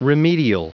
Prononciation du mot remedial en anglais (fichier audio)
Prononciation du mot : remedial